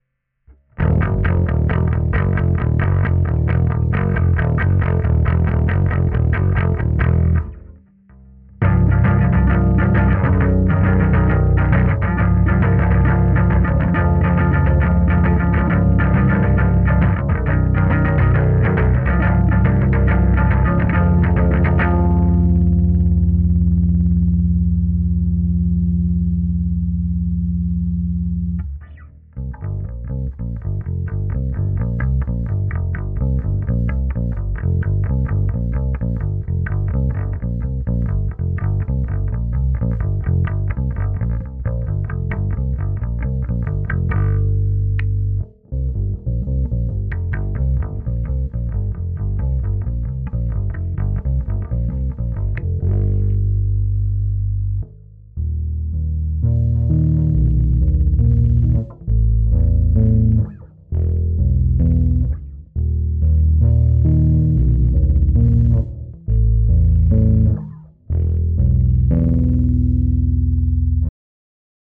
Spravil som si radost novym mikrofonom (Oktava MK319), tak som vam nieco rychle rychlo nahral:
Basa, Oktava pri 10" repraku:
To je EZ Drummer.